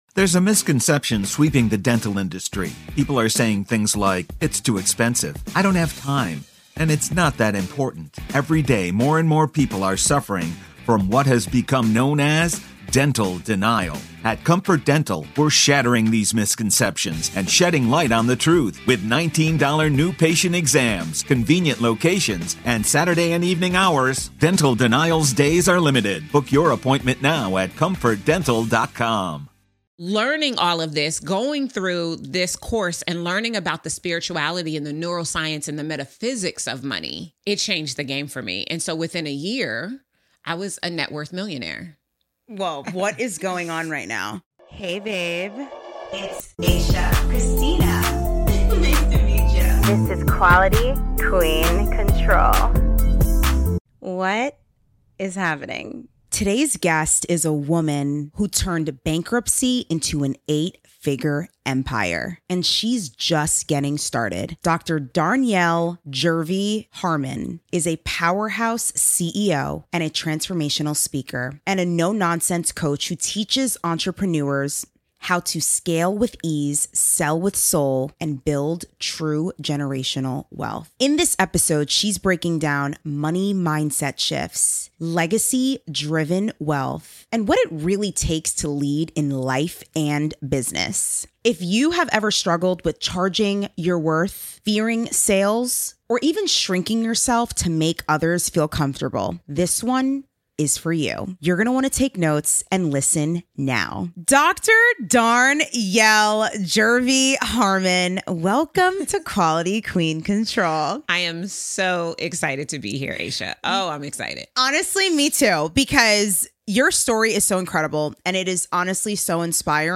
This is a conversation about resilience, strategy, and the power of never giving up.